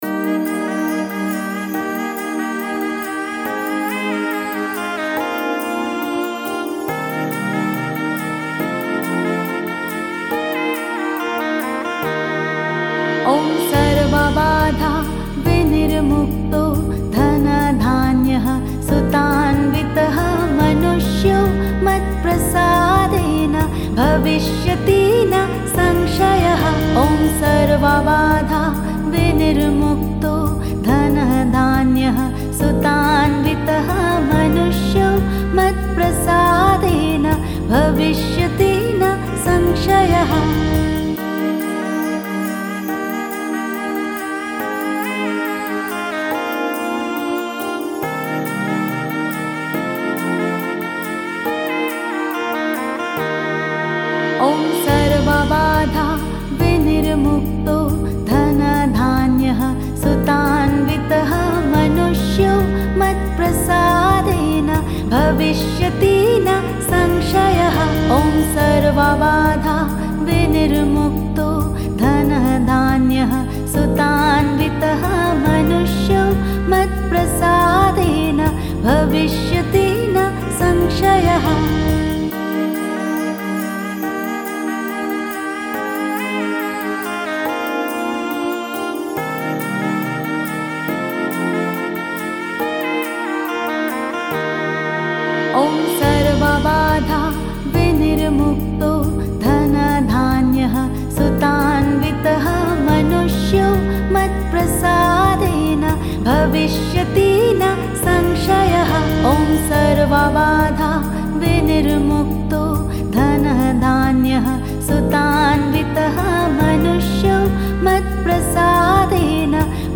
Chant in Sanskrit